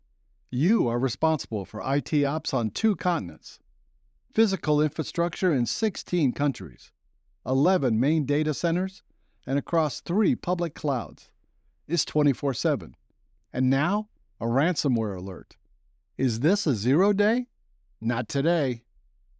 My voice is professional, upbeat, enthusiastic, sensual, fresh, warm, narrator, instructive, clear, corporate, friendly, strong, likable, happy, informative, conversational, animated, natural, articulate, versa...